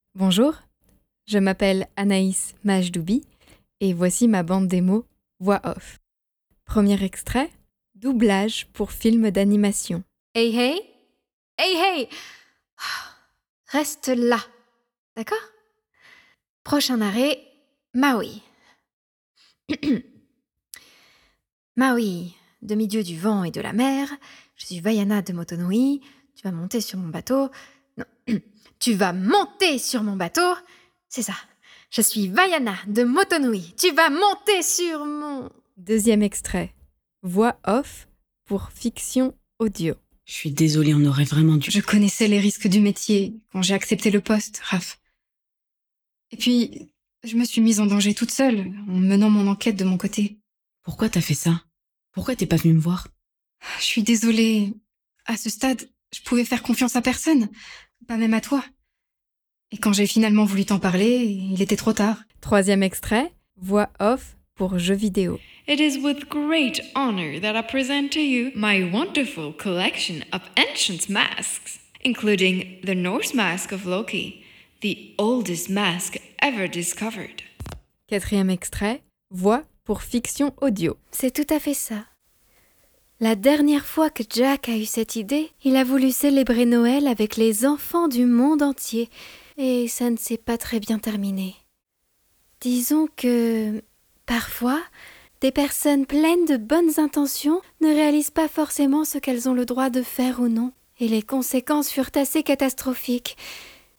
Démo voix-off
Voix off
- Mezzo-soprano